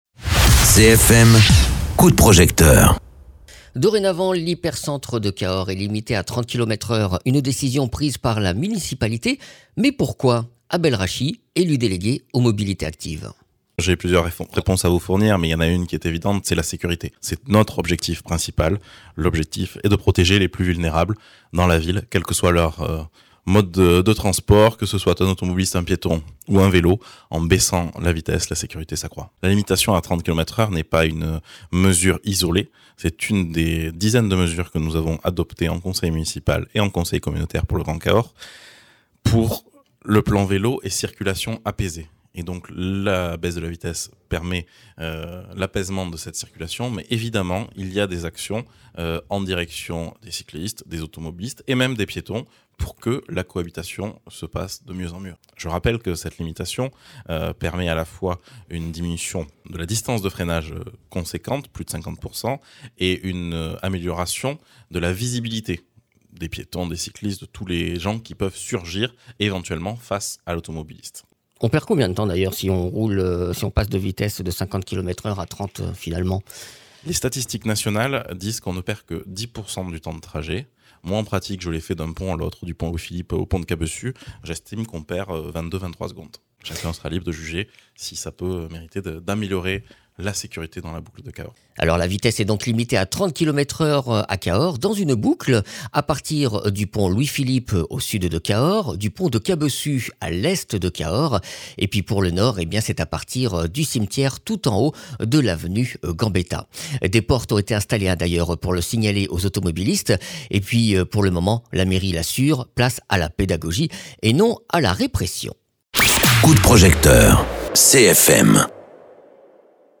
Interviews
Invité(s) : bel Rachi, élu délégué aux mobilités actives à la ville de Cahors